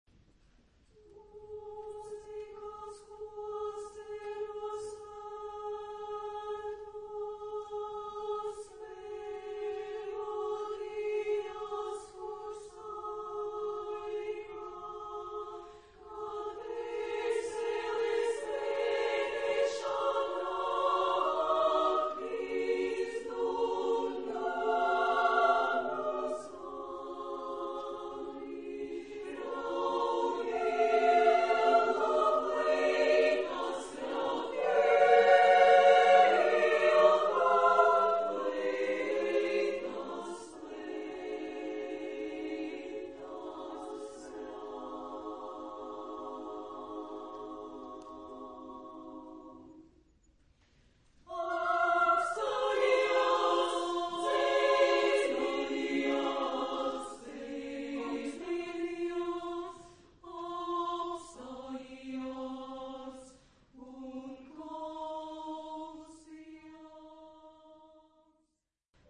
Type de matériel : Partition pour choeur et soliste(s) seuls
Type de choeur : SSAA  (4 voix égales de femmes )
Réf. discographique : Florilège Vocal de Tours, 2005